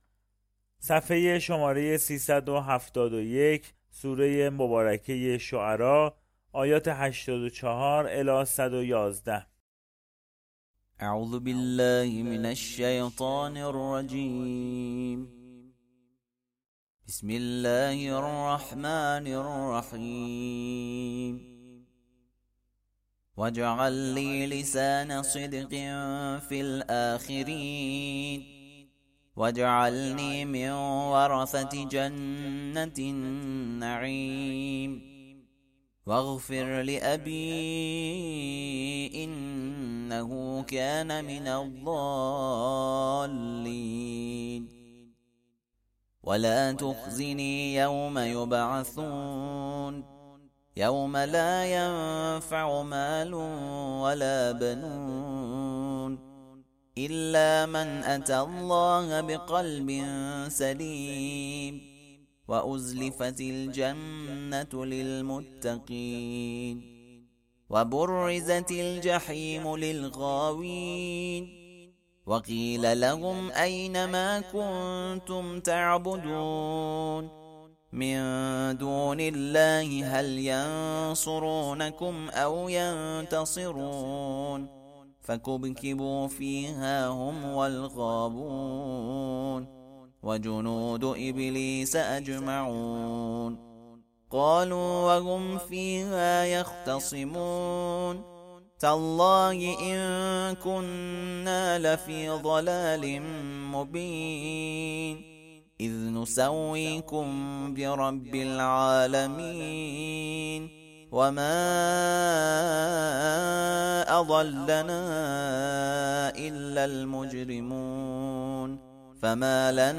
ترتیل صفحه 371 کلام الله مجید+ صوت